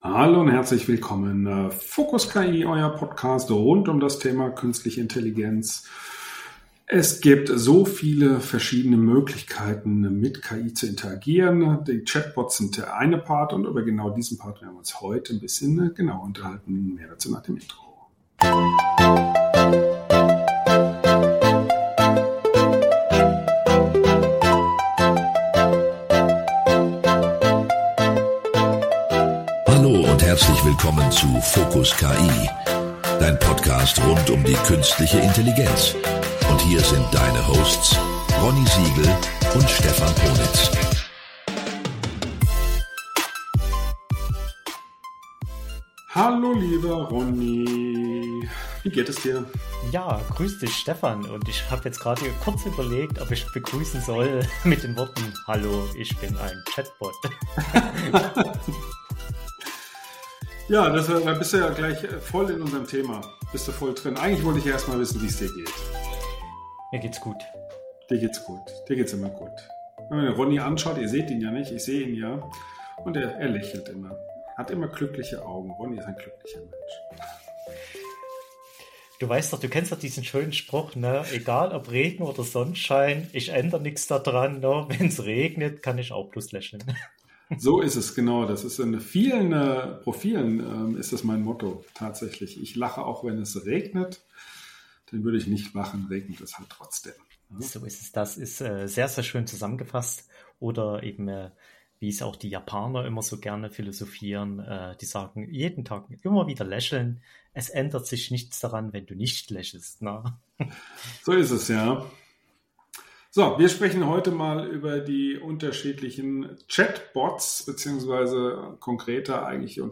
Sie vergleichen die Stärken und Schwächen von ChatGPT, Perplexity, Microsoft Copilot und Gemini, und erörtern, welche Anwendungen für welche Chatbots am besten geeignet sind. Die Diskussion beleuchtet die Nutzungserfahrungen der beiden Moderatoren und gibt Einblicke in die Zukunft der KI-gestützten Technologien.